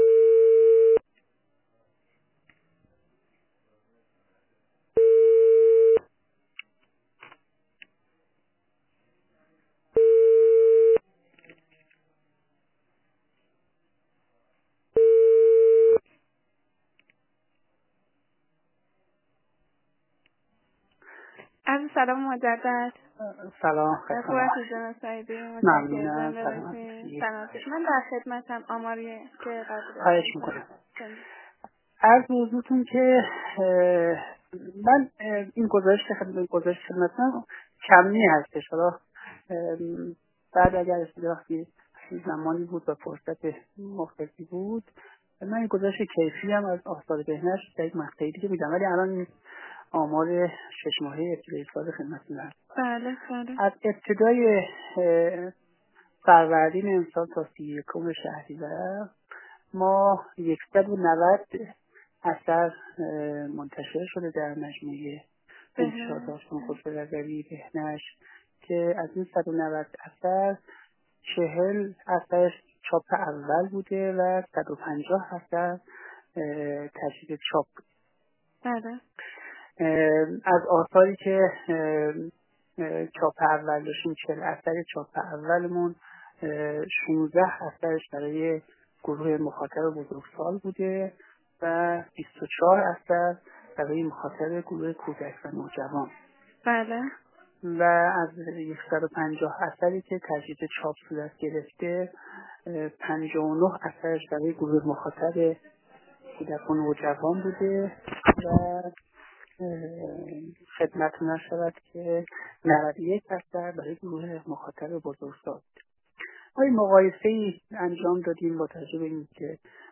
در گفت‌وگو با خبرنگار ایکنا از رشد 25 تا 30 درصدی تولیدات این انتشارات در نیمه اول سال جاری خبر داد و گفت